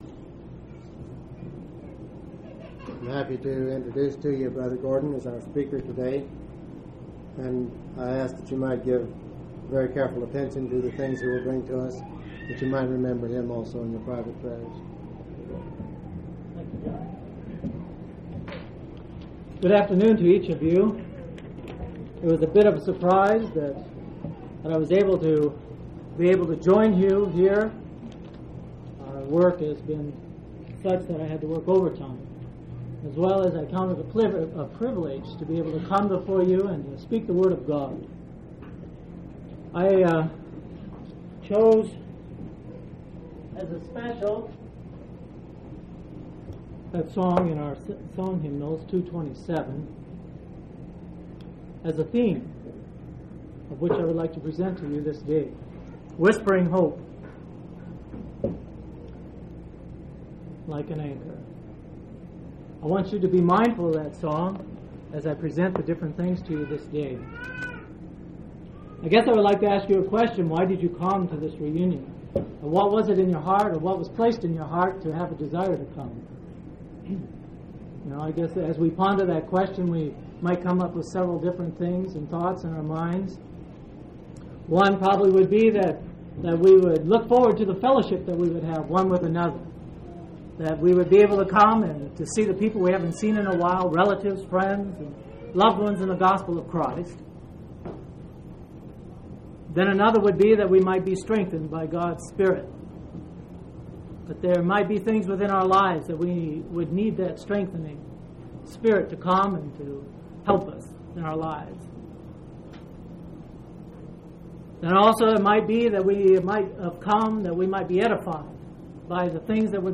6/12/1988 Location: Colorado Reunion Event